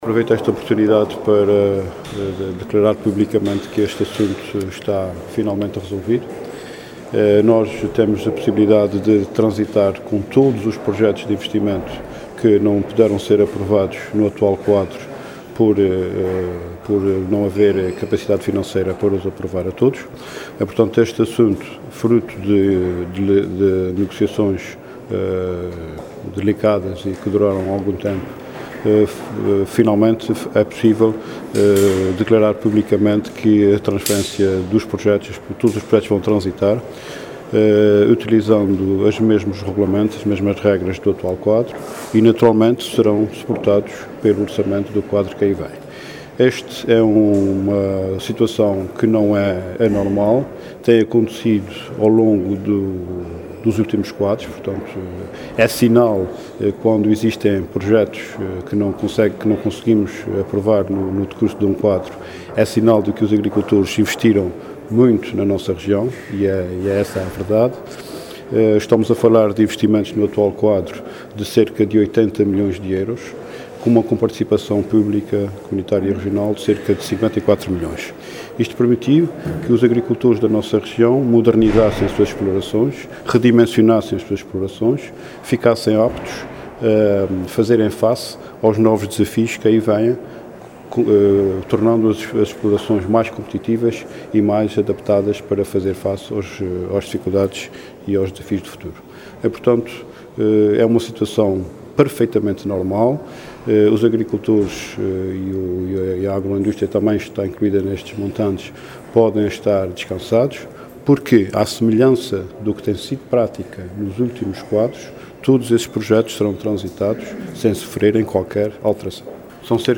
O Secretário Regional, que falava à margem da sessão plenária da Assembleia Legislativa, frisou que está, assim, assegurado o financiamento de cerca de 33 milhões necessários à concretização dos projetos de investimento em causa, lamentando a figura regimental utilizada esta manhã pelo PSD que não permitiu ao Governo prestar este esclarecimento em plenário.